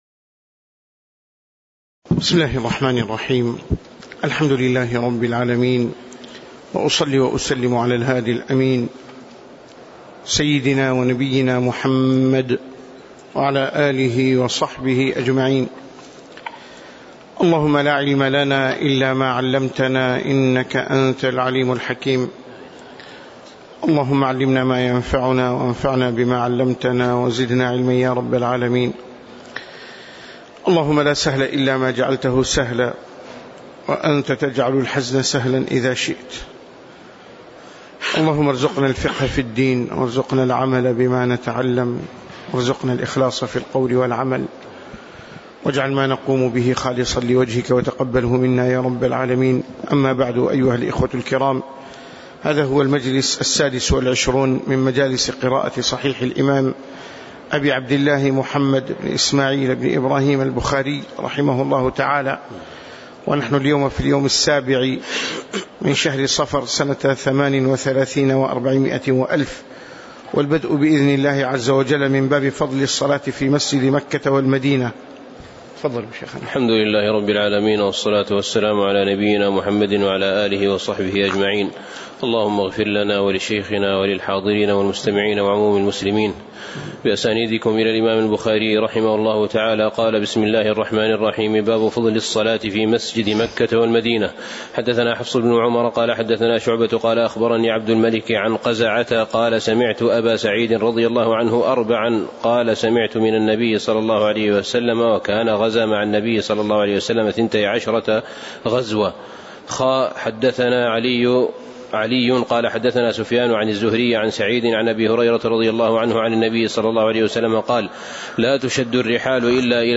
تاريخ النشر ٧ صفر ١٤٣٨ هـ المكان: المسجد النبوي الشيخ